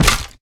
hurt2.ogg